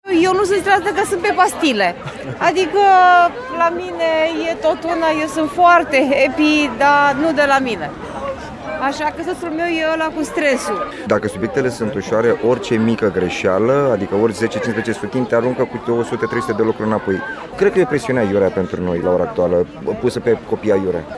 vox-parinti-combinat-.mp3